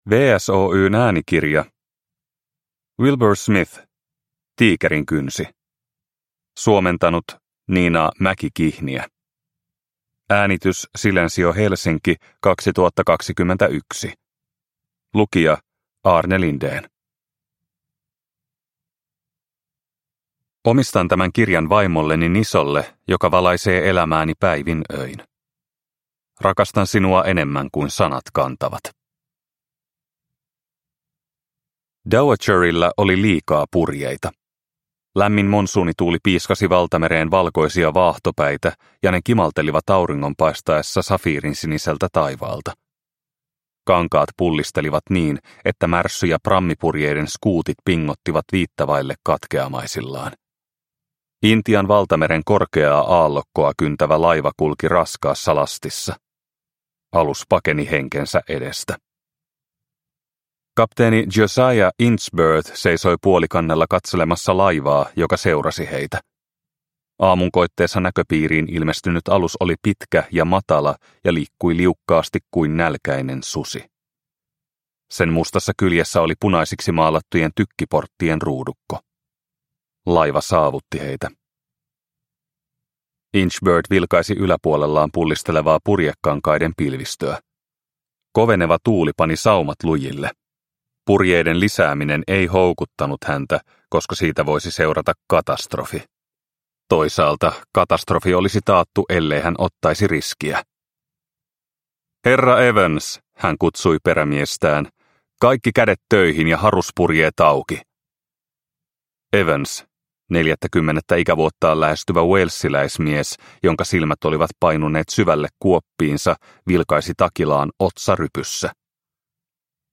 Tiikerinkynsi – Ljudbok – Laddas ner